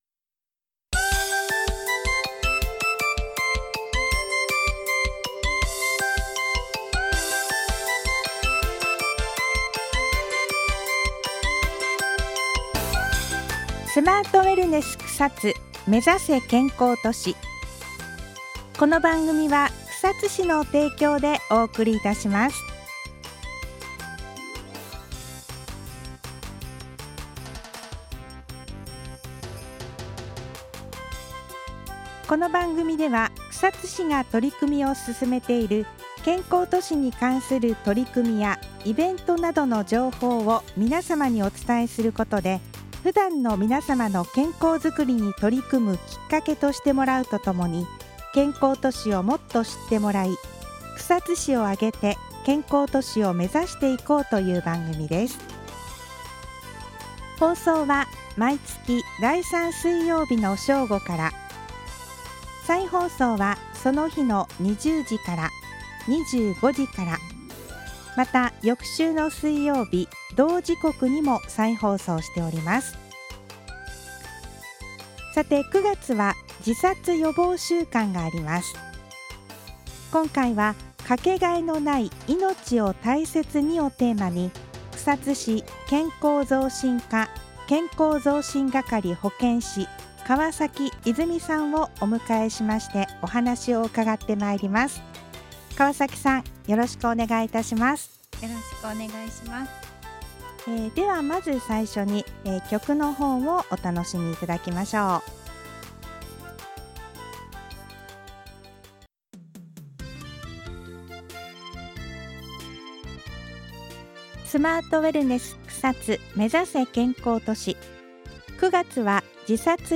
※著作権の関係で、音楽は削除しています。